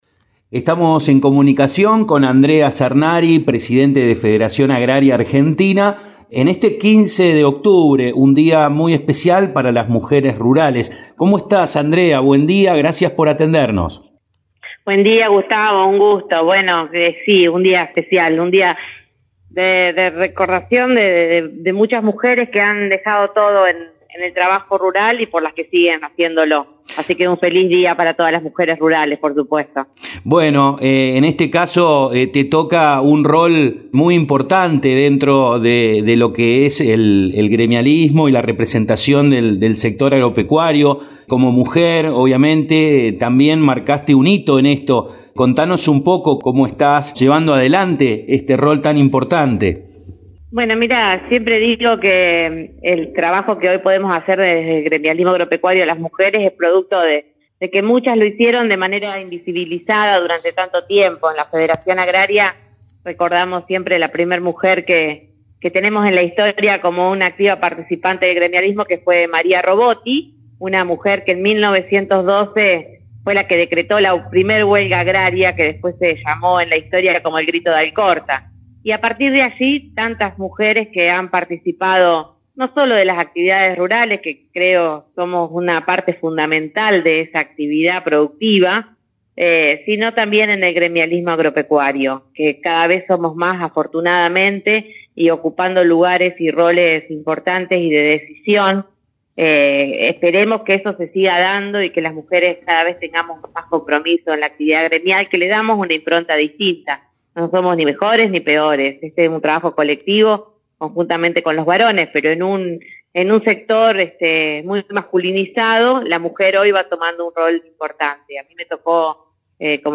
resalta en diálogo con El Campo Hoy la importancia de las mujeres en el sector agropecuario.